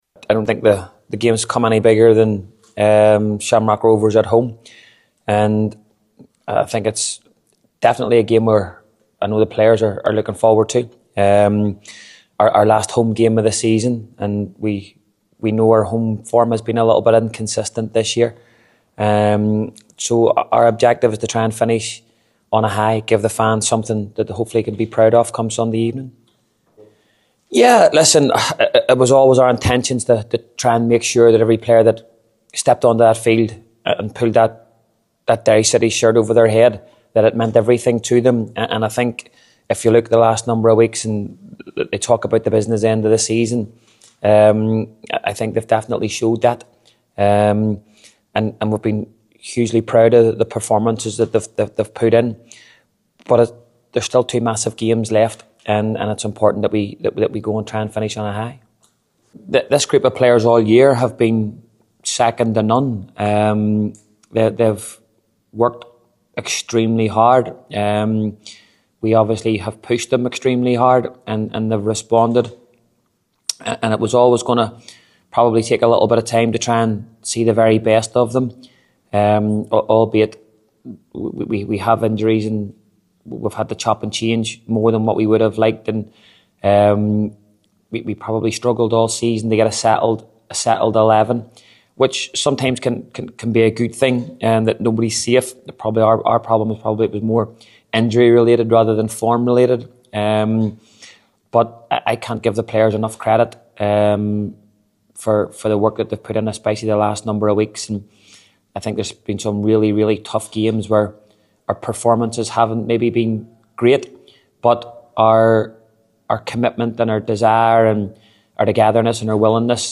spoke to the assembled media in the build up to Sunday’s game